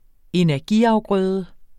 Udtale [ enæɐ̯ˈgi- ]